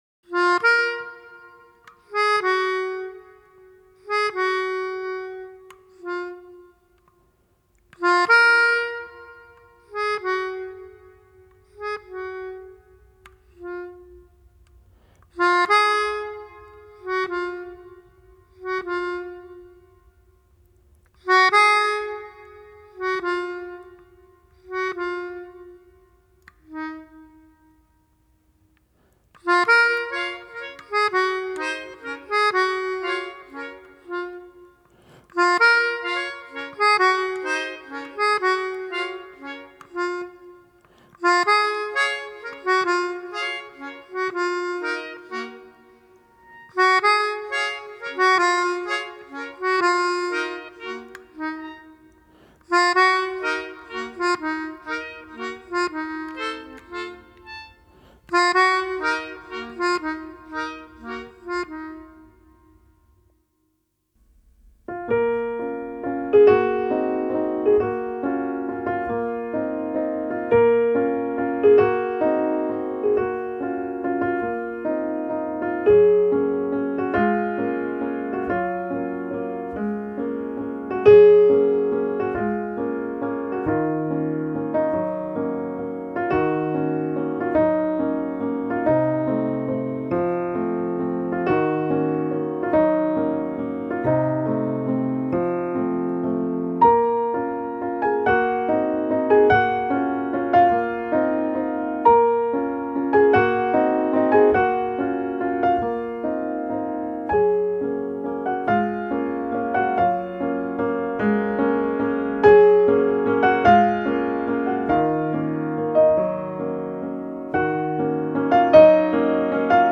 موسیقی متن